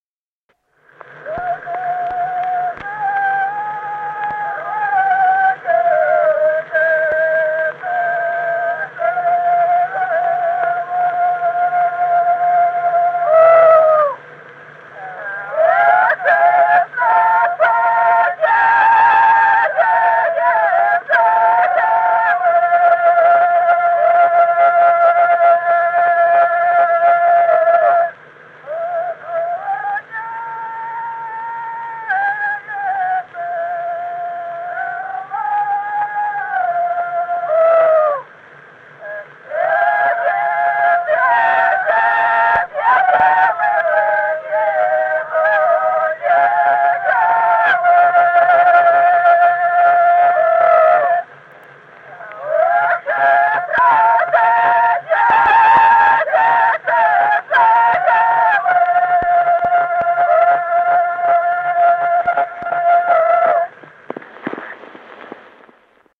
Песни села Остроглядово. Да за горою жито жала.